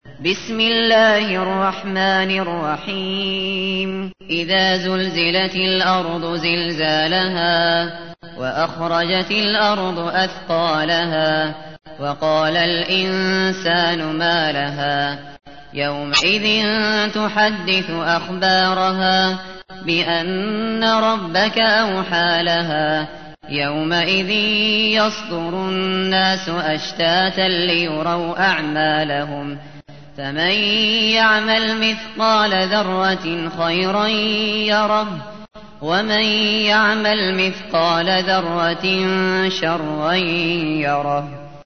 تحميل : 99. سورة الزلزلة / القارئ الشاطري / القرآن الكريم / موقع يا حسين